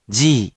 We’re going to show you the character, then you you can click the play button to hear QUIZBO™ sound it out for you.
In romaji, 「ぢ」 is transliterated as 「di」which sounds SORT of like the letter 「D」**but varies based on dialect ranging from  /ʒi/ to /ʤi/ to /ʑi/